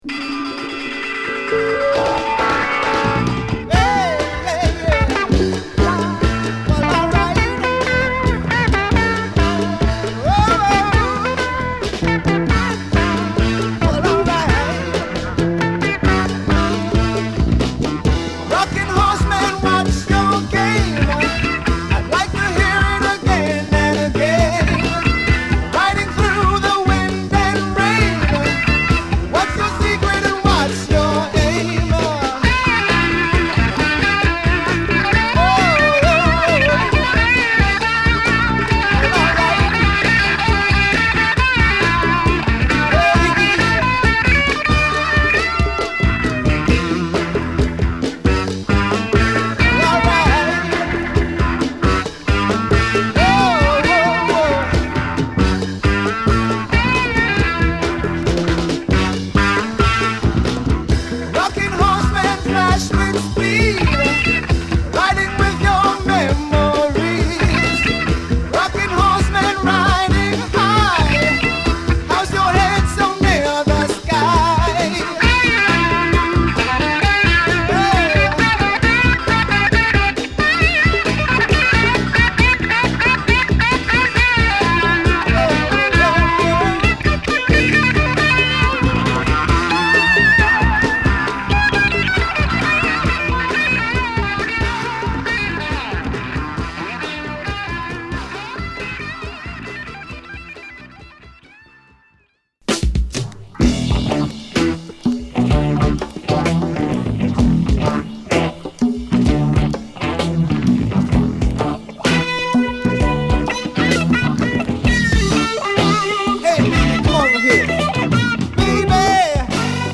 UKの人種混合ロック・バンド